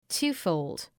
Προφορά
{‘tu:fəʋld}